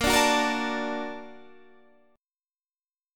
Listen to Bb+M7 strummed